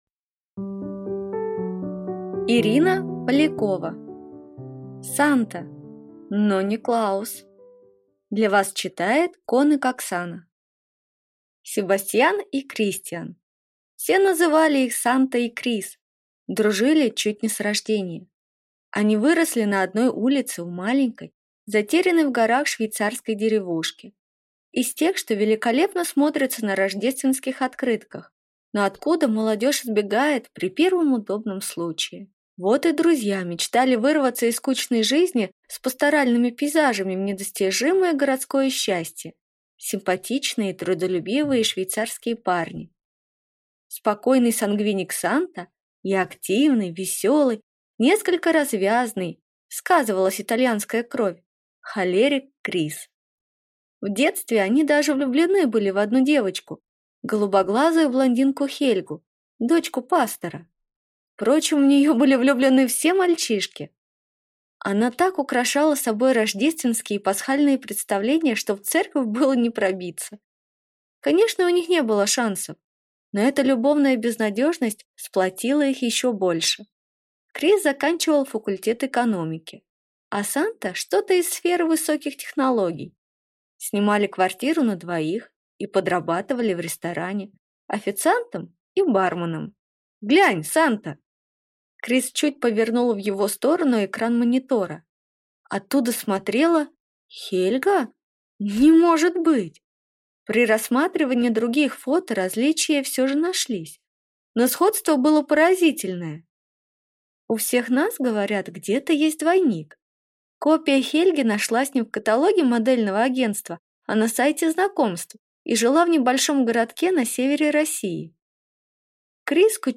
Аудиокнига Санта, но не Клаус | Библиотека аудиокниг